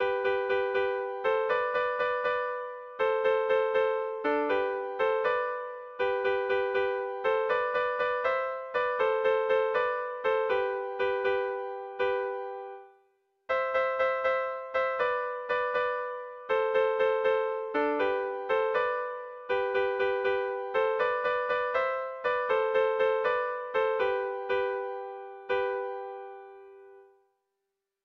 Air de bertsos - Voir fiche   Pour savoir plus sur cette section
Dantzakoa
A-B-C-D-AB